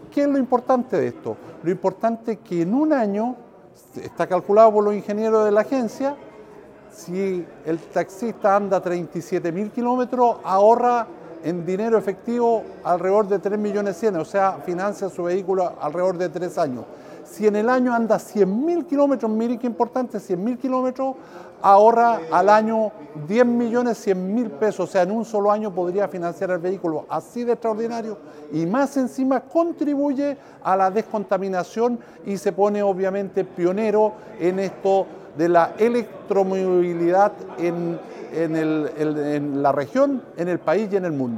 Por su parte, el seremi de Energía, Jorge Cáceres, destacó la importancia de esta acción.